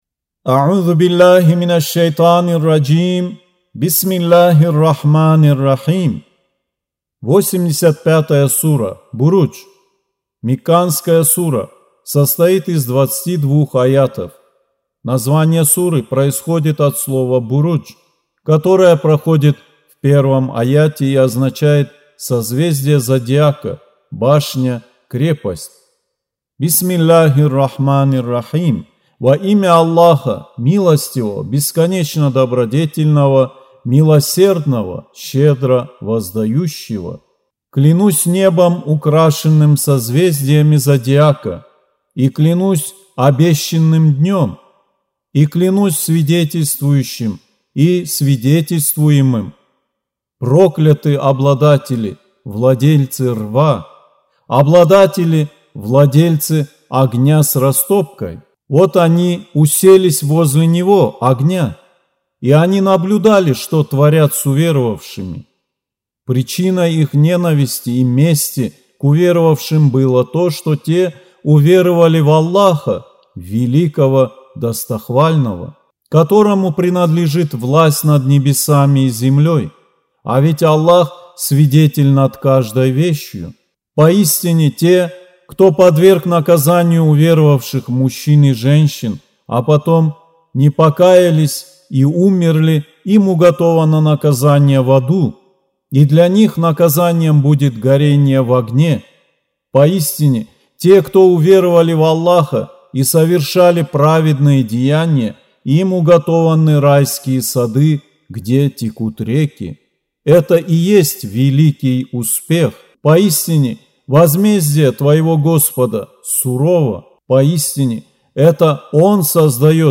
Аудио Коран 85.